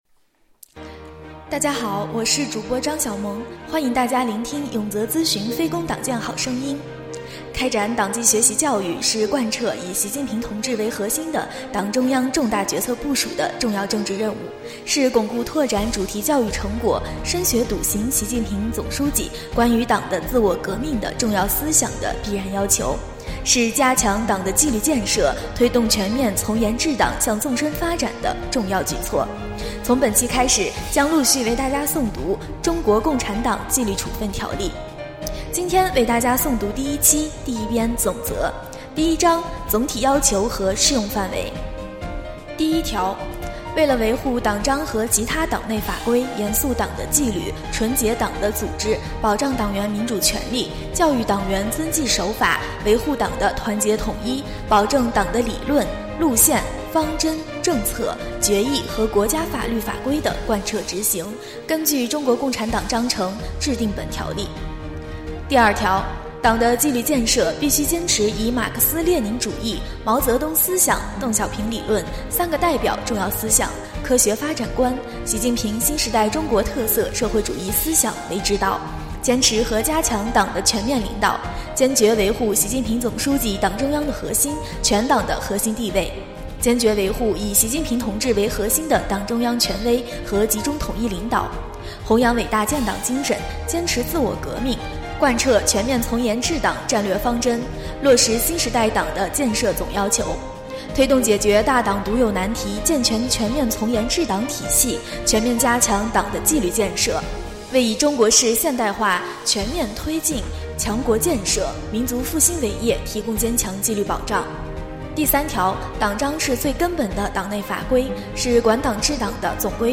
诵读《中国共产党纪律处分条例》第一期-永泽党建